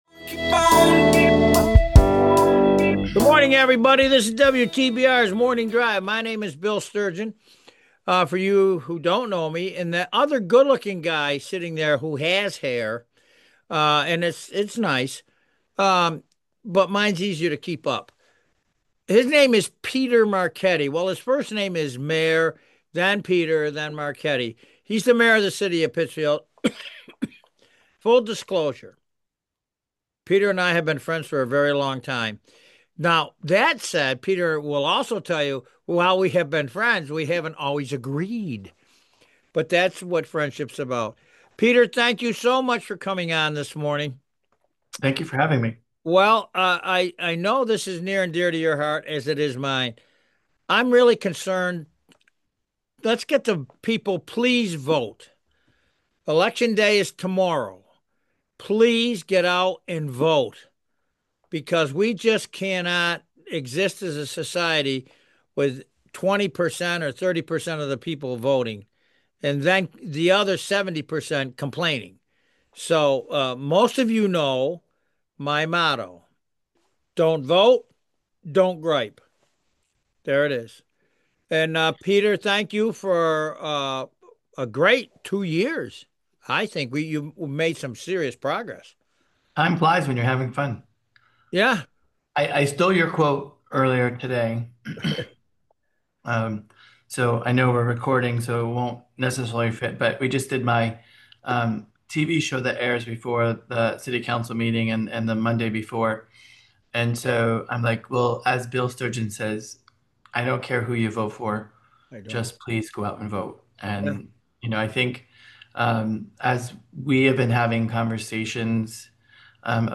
spends the hour talking with Pittsfield Mayor Peter Marchetti.